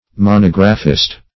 Monographist \Mo*nog"ra*phist\, n. One who writes a monograph.
monographist.mp3